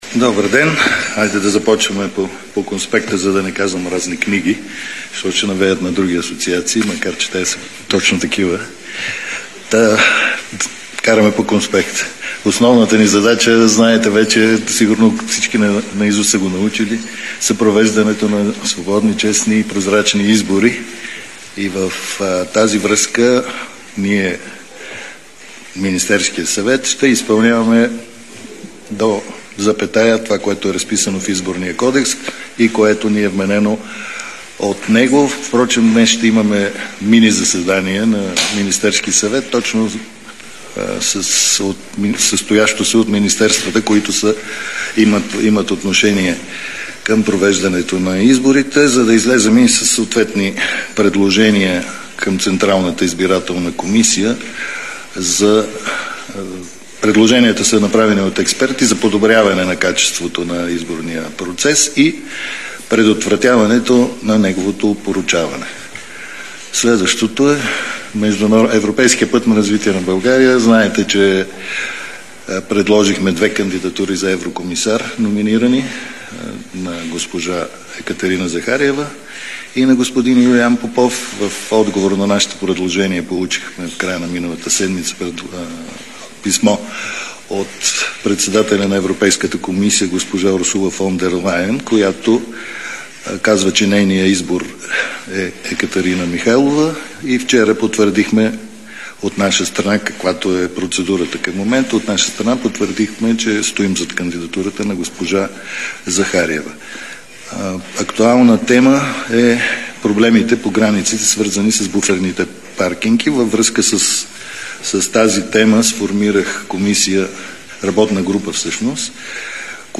10.40 - Брифинг на съпредседателя на ПП Кирил Петков. - директно от мястото на събитието (Народното събрание)
Директно от мястото на събитието